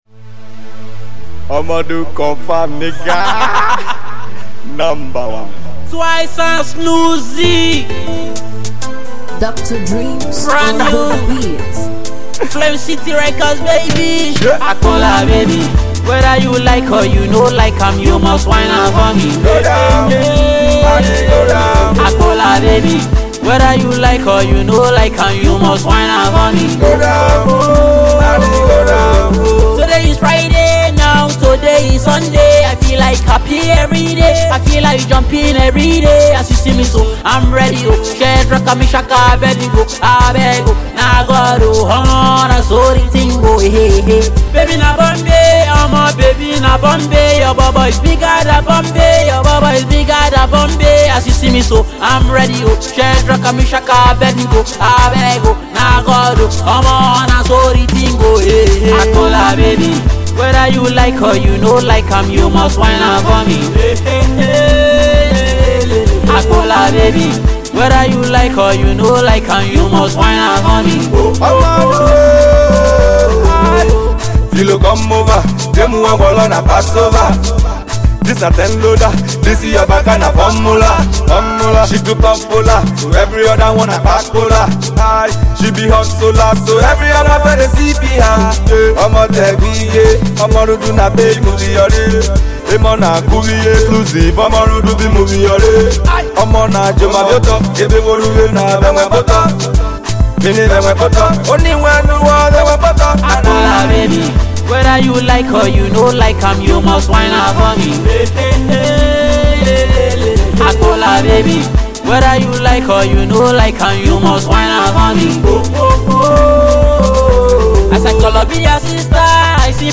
Indigenous Pop